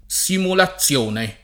[ S imula ZZL1 ne ]